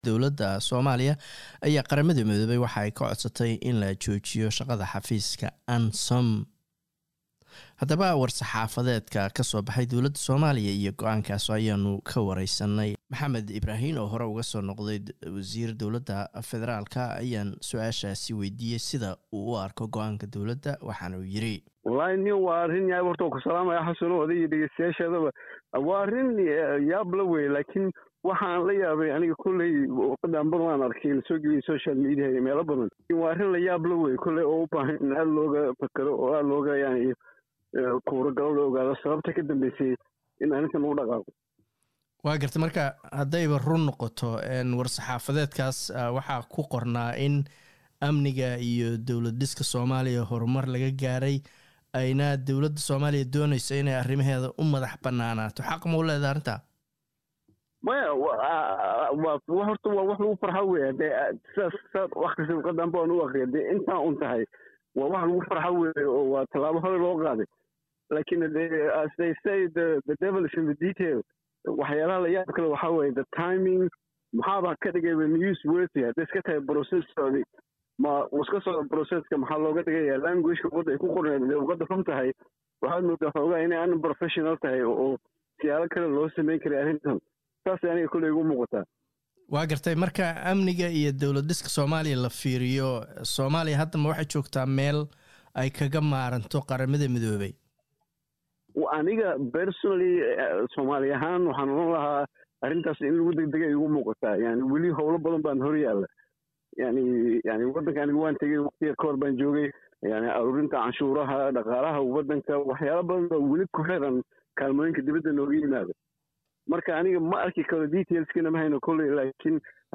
oo aan ka waraysanay go'aanka Dawladda Federalka Soomaaliya ay ku joojinayso shaqada UNSOM